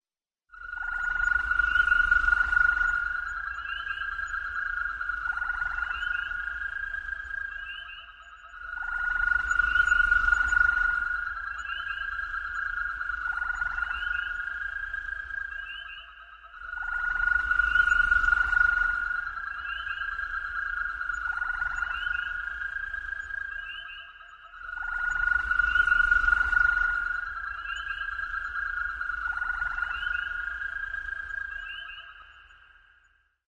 描述：Wind Tryst Farm的春天氛围。记录器从商店的橱窗。在春天充满水的小池塘，回响温暖的天气。从Vesta Fire Multitrack Recorder中回收。记录1992年4月。你在那里。